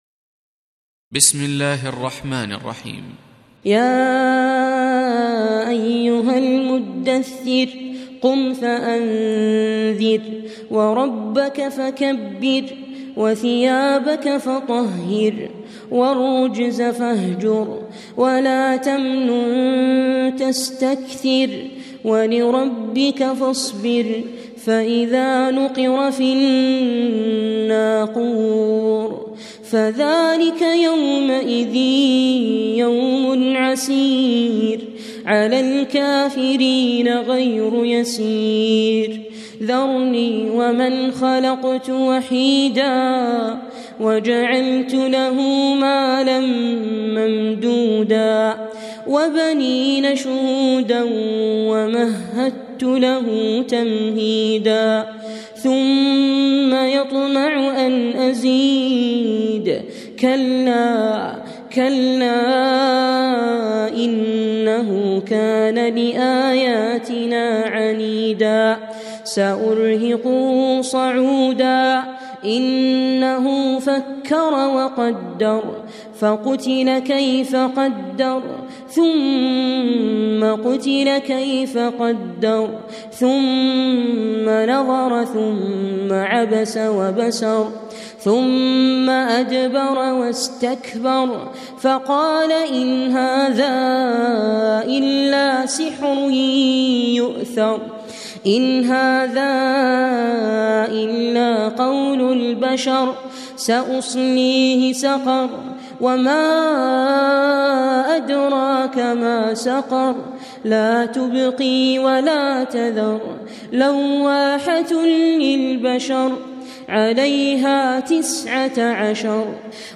Reciting Murattalah Audio for 74. Surah Al-Muddaththir سورة المدّثر N.B *Surah Includes Al-Basmalah